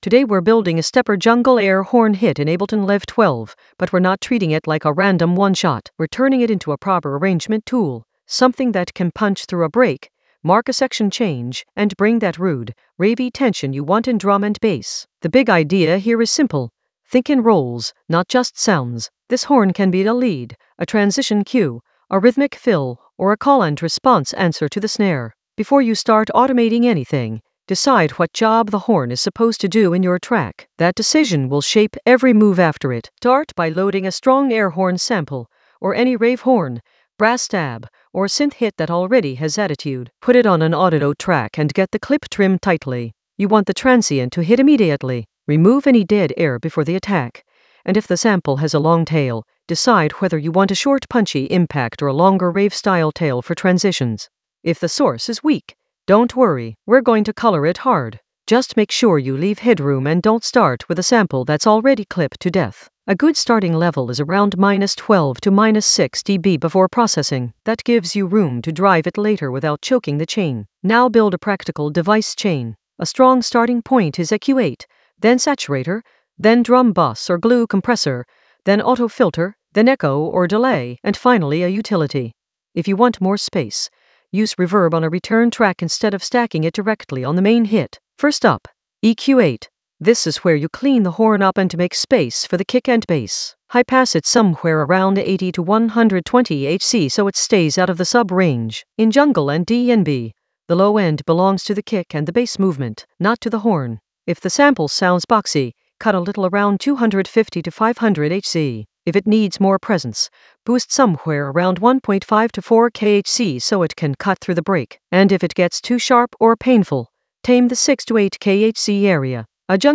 An AI-generated advanced Ableton lesson focused on Stepper jungle air horn hit: color and arrange in Ableton Live 12 in the Drums area of drum and bass production.
Narrated lesson audio
The voice track includes the tutorial plus extra teacher commentary.